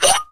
NY CUP RING.wav